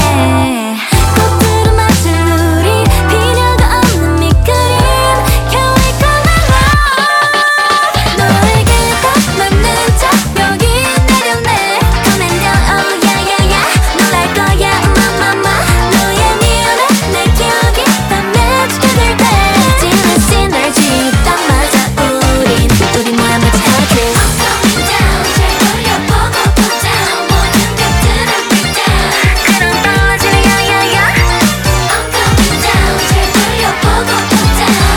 Жанр: Танцевальные / Русские
# Dance